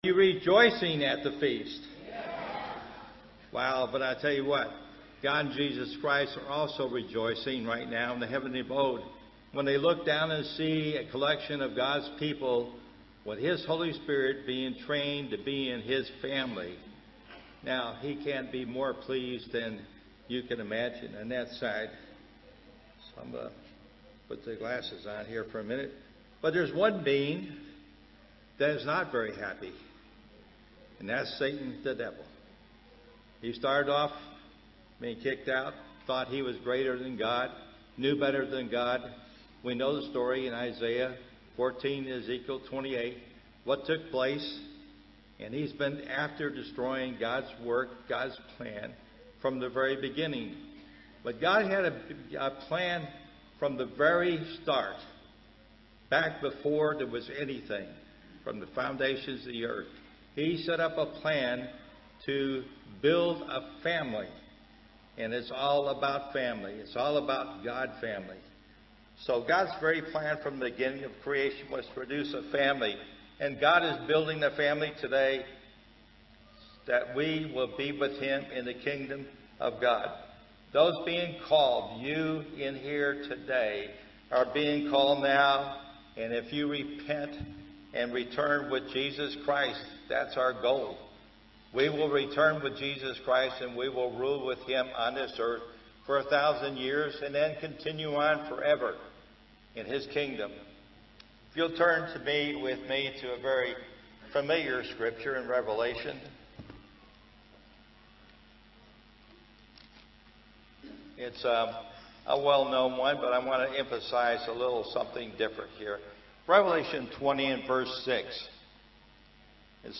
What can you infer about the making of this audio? This sermon was given at the Montego Bay, Jamaica 2019 Feast site.